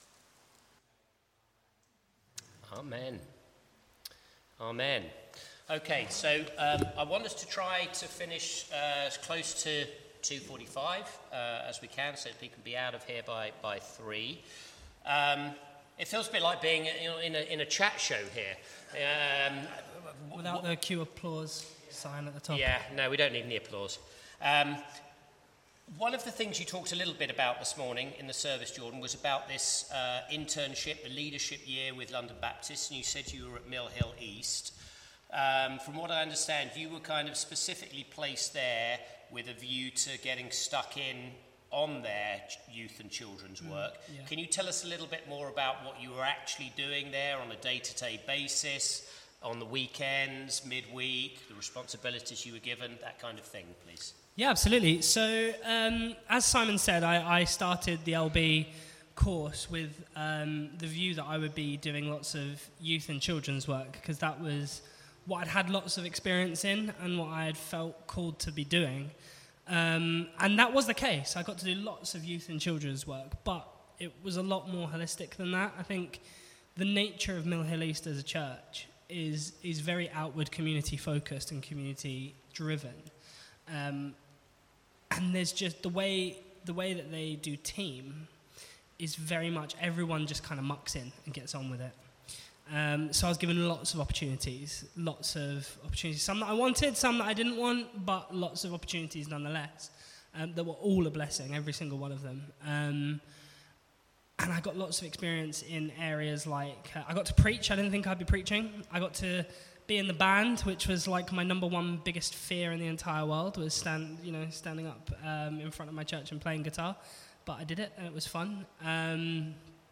Media for Sunday Service on Sun 29th Jun 2025 13:30 Speaker